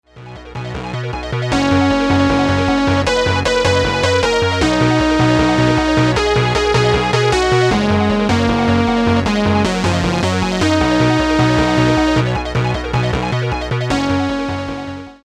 a PLD-based C64 SID emulation
The sound module is changed that way that it emulates the famous SID in the Commodore 64.
The fundamental waves are TRIANGLE, SQUARE, SAWTOOTH + NOISE.
Filtering and some analog behaviour is included too to tweak the sound.
Also a simple version of the distortion module is integrated.
C64-SID-Sound Example
c64sidemu.mp3